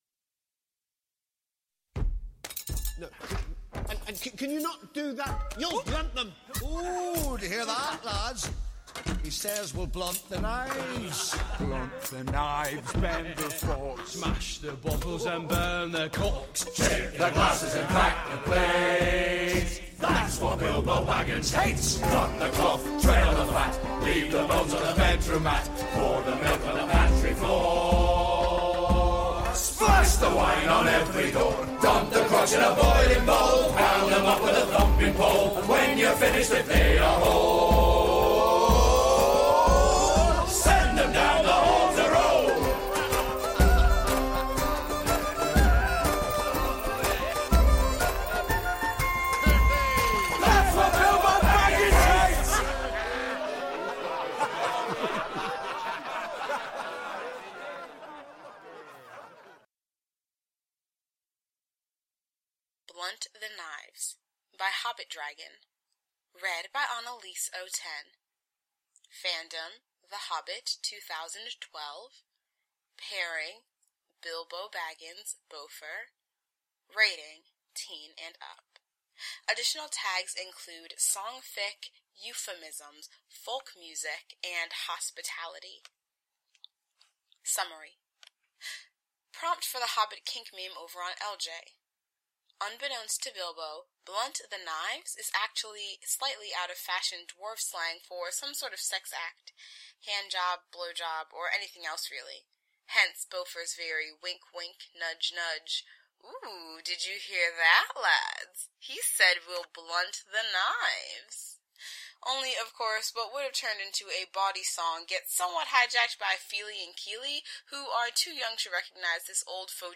[Podfic] Blunt the Knives